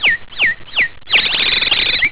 Bird 2
BIRD_2.wav